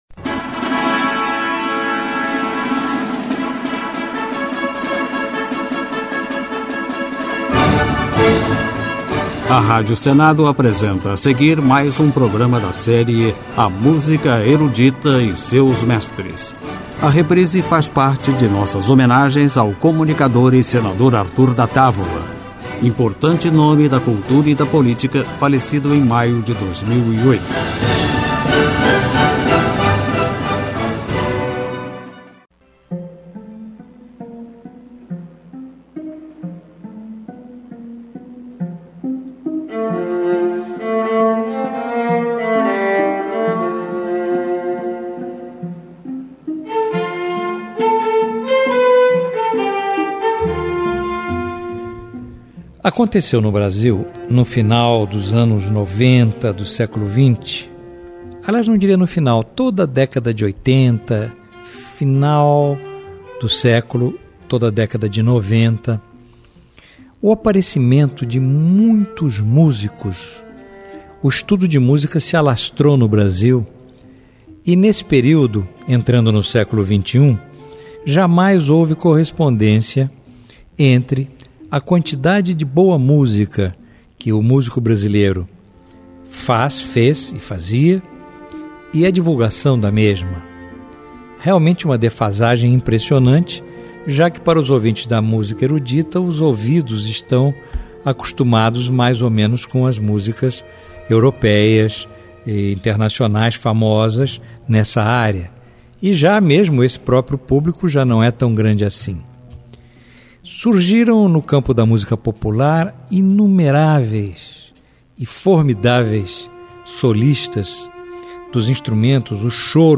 Música Erudita